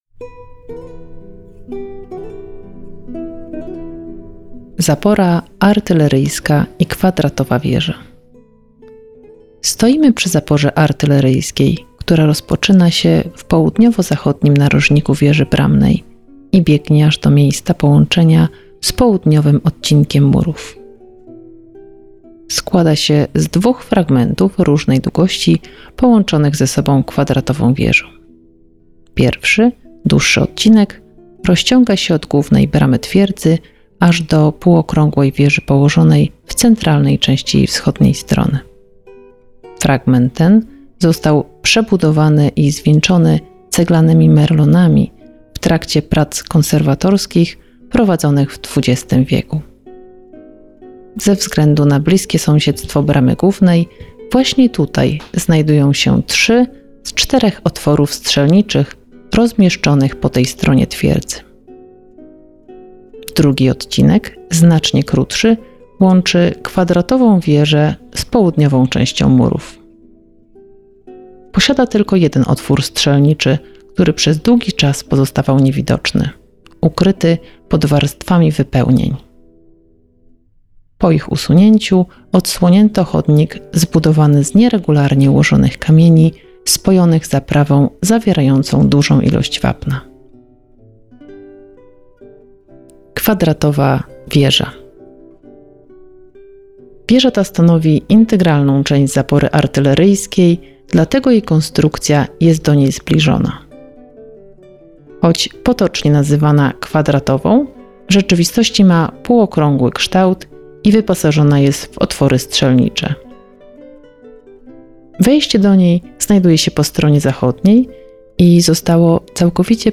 Zamek Salobreña – Zwiedzanie z audioprzewodnikiem